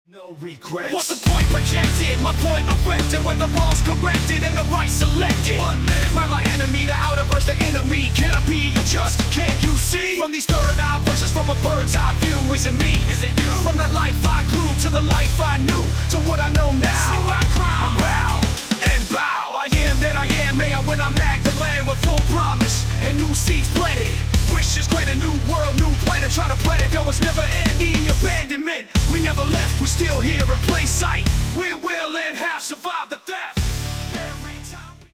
Short version of the song, full version after purchase.
An incredible Hip Hop song, creative and inspiring.